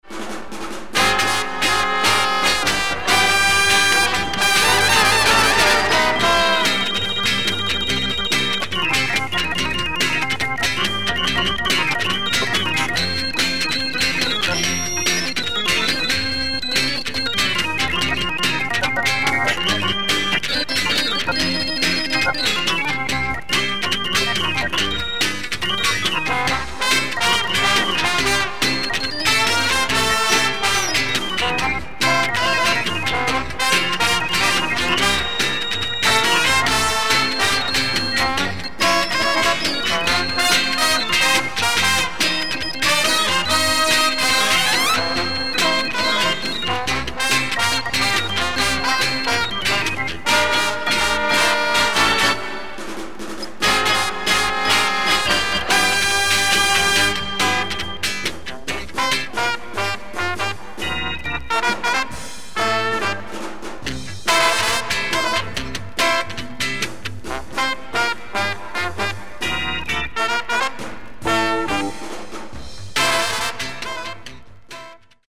Jazz pop big band from Finland
with nice organ
Pop Jerk